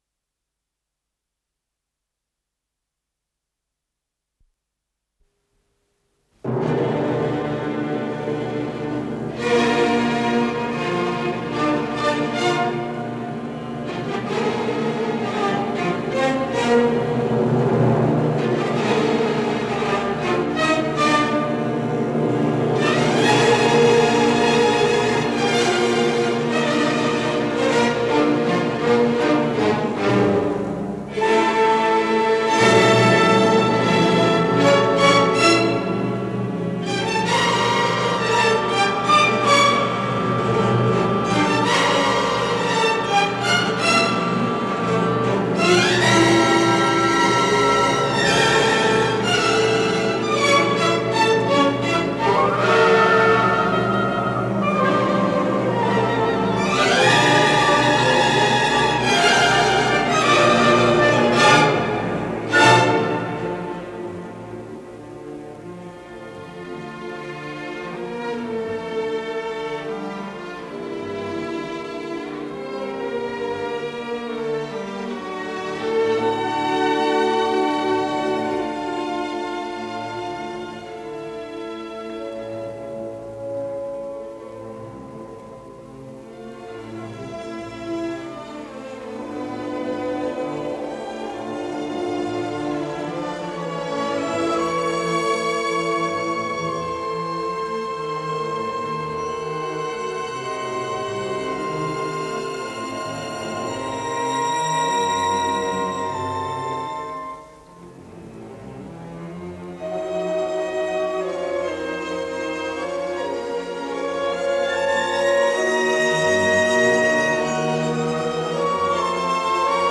ПКС-08601 — Концерт для фортепиано с оркестром №1, соч. 15, ре минор — Ретро-архив Аудио
Исполнитель: Вильгельм Бакхауз - фортепиано
ре минор